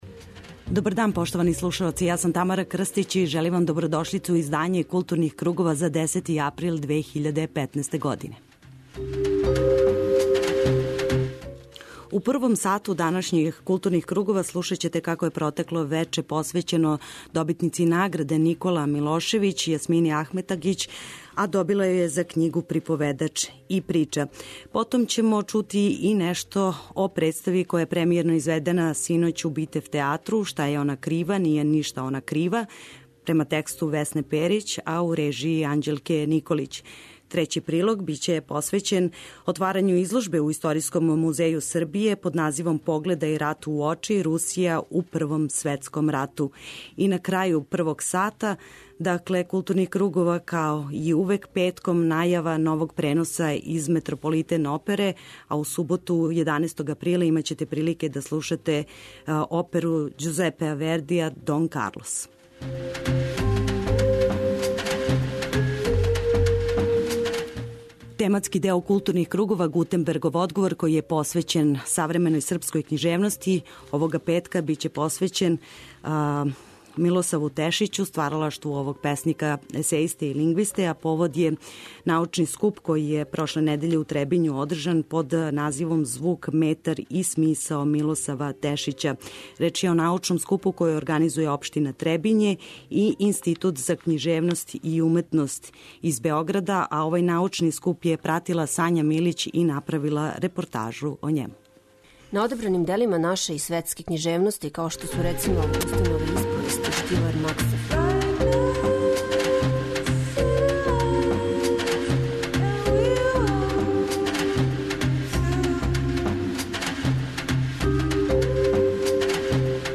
преузми : 52.43 MB Културни кругови Autor: Група аутора Централна културно-уметничка емисија Радио Београда 2.